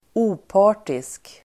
Uttal: [²'o:pa:r_tisk]